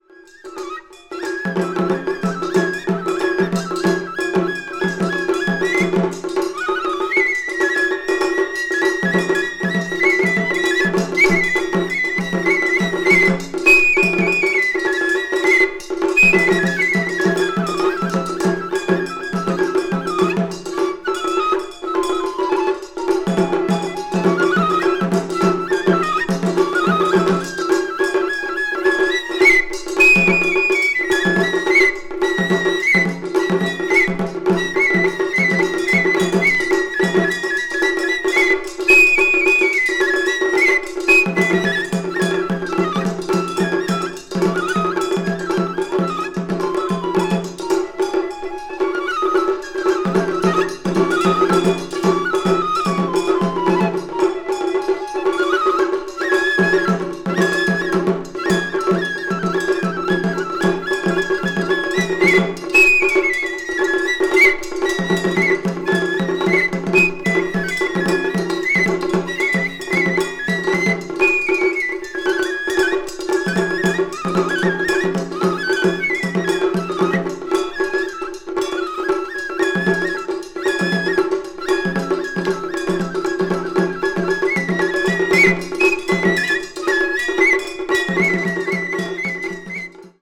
media : EX-/EX+(わずかにチリノイズが入る箇所あり,A:再生に影響ないわずかな凹上の反りあり)
east asia   ethnic music   festival music   japan   traditional